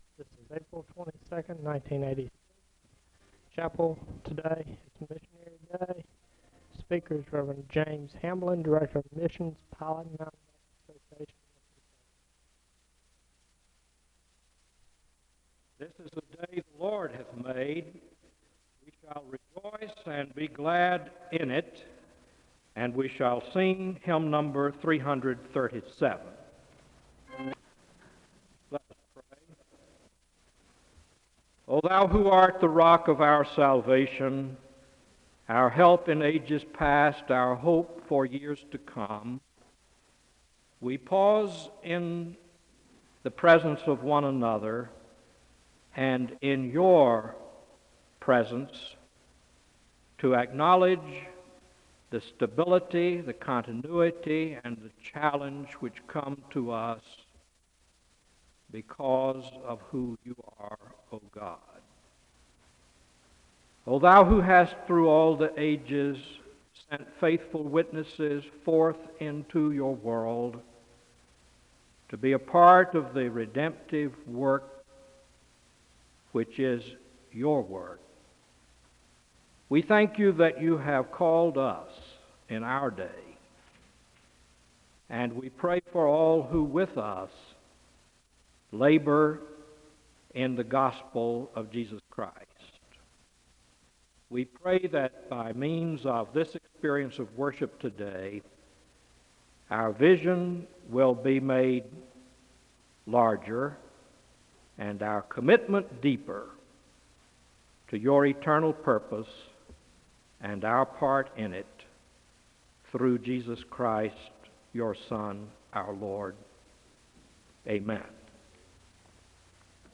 Portions of the audio are inaudible.
The service begins with a moment of prayer (0:00-1:58). The choir sings a song of worship (1:59-5:10).
The service closes with a moment of prayer (38:56-40:47).